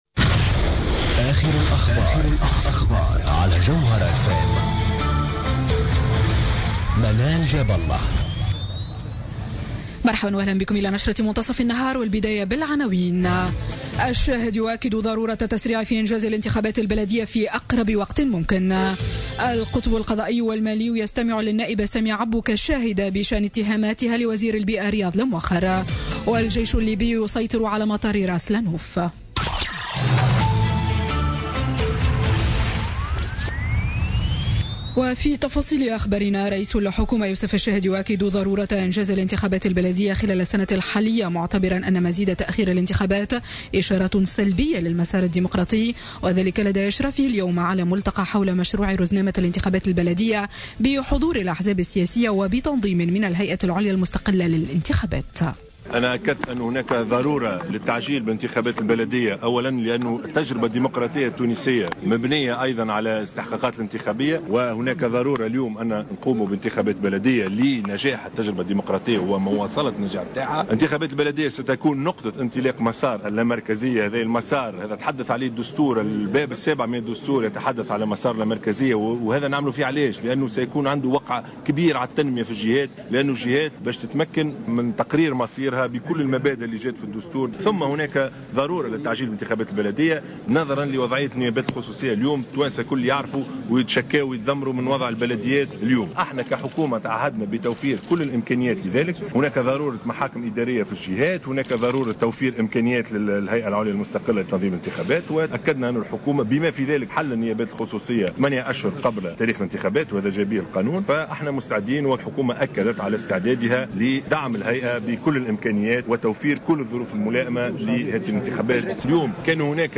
نشرة أخبار منتصف النهار ليوم الثلاثاء 14 مارس 2017